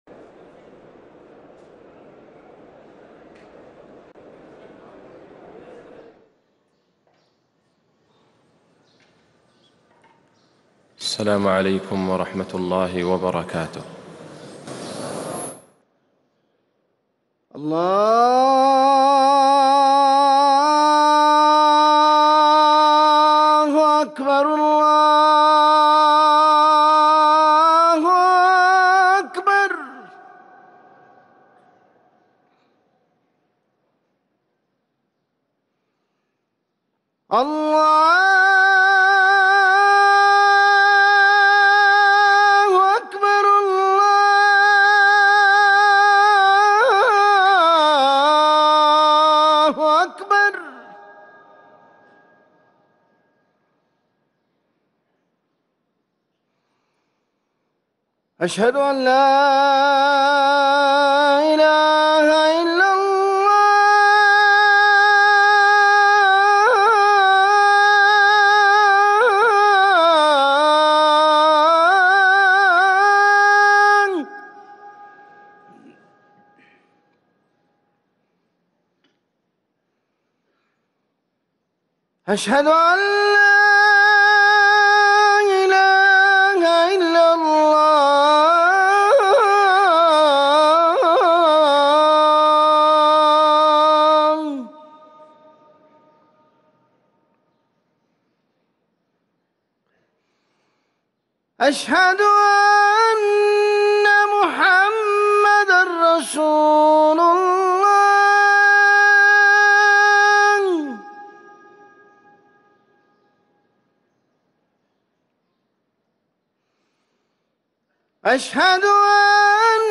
اذان الجمعة الثاني
ركن الأذان